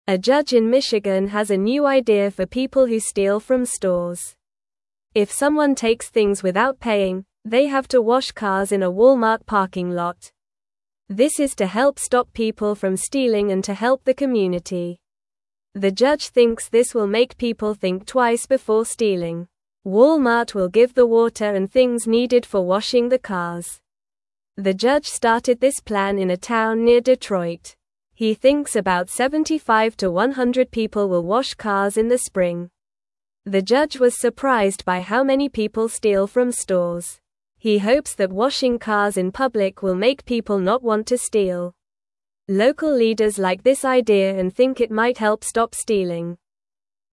Normal
English-Newsroom-Beginner-NORMAL-Reading-Judges-New-Plan-to-Stop-Store-Stealing.mp3